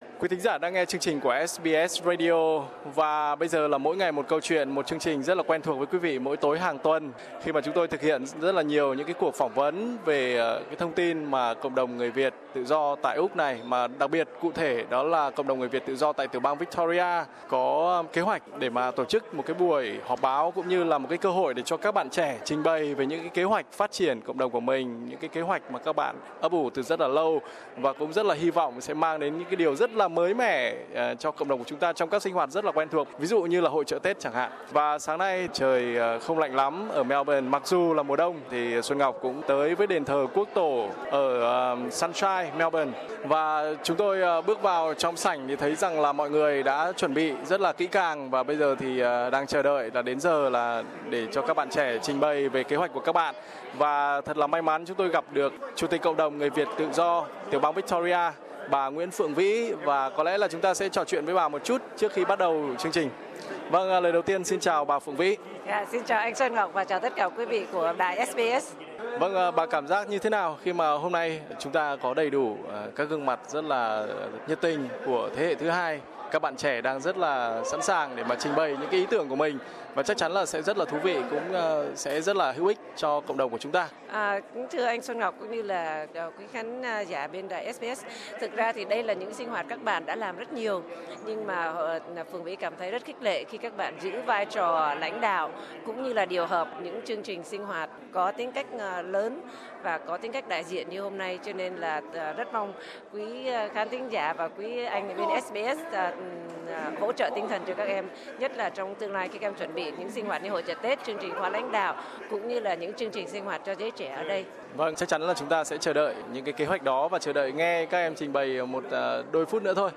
Trưa Chủ Nhật 10/07/2016 tại Đền Thờ Quốc Tổ ở Melbourne, các bạn trẻ thế hệ thứ hai người Việt tại Úc trả lời họp báo, trình bày các dự án phát triển cộng đồng và đặc biệt khẳng định các sinh hoạt trong tương lai sẽ mới mẻ và ấn tượng.